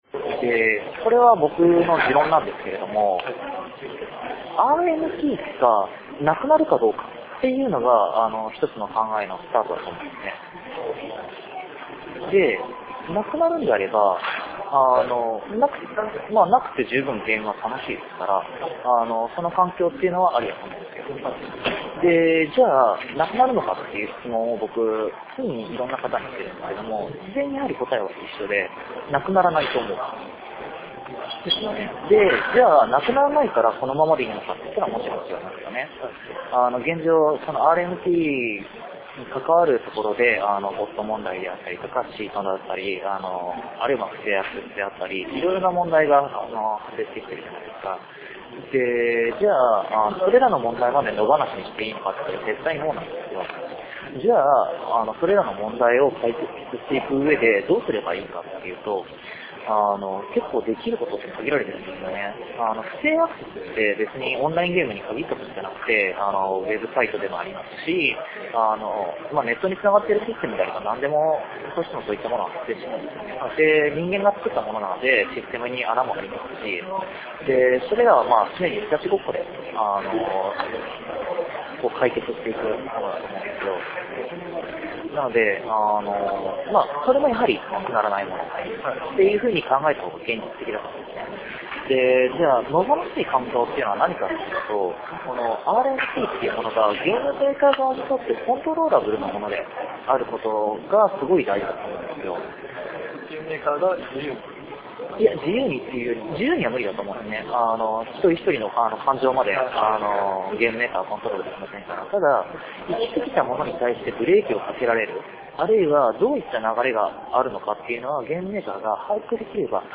MMOに関わる様々な人との対談、インタビューなどをそのまま保存しています。
生の状況をそのまま感じれるよう、録音時の会話をそのままアップしている時もあります。